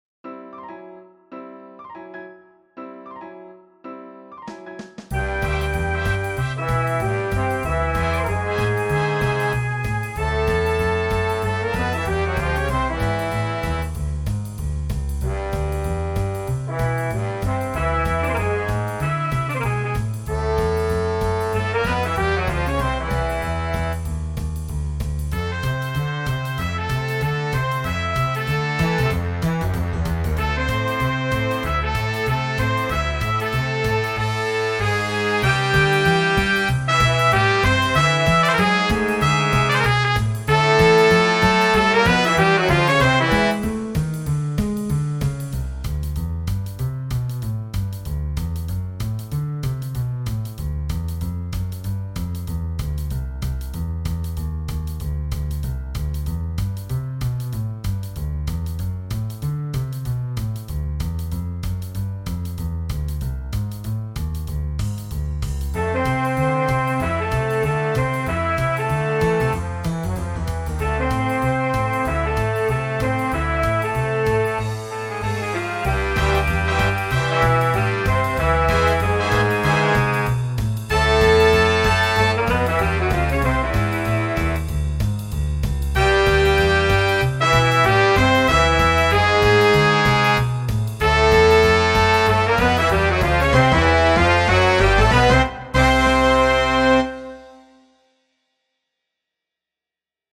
For Jazz Ensemble